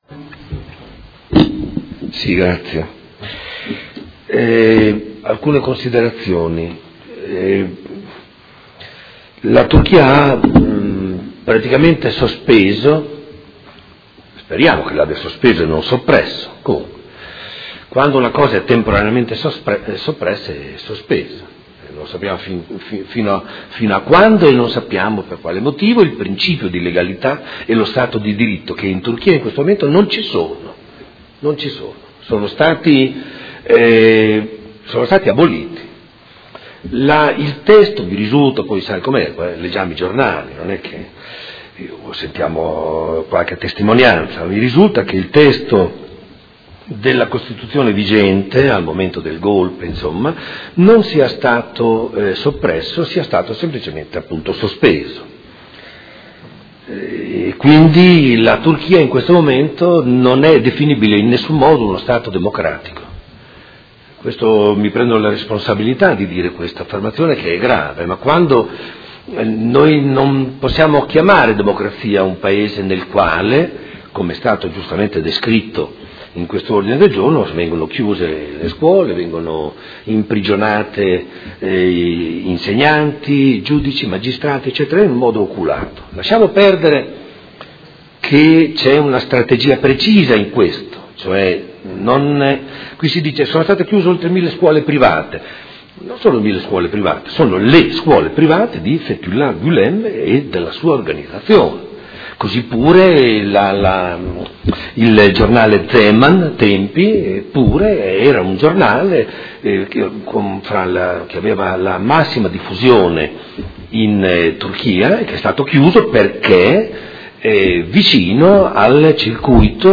Seduta del 27/01/2016 Presenta emendamento nr 157857 a Ordine del Giorno presentato dai Consiglieri Pellacani e Galli (F.I.) avente per oggetto: Condanna delle epurazioni e degli arresti in Turchia e solidarietà alle vittime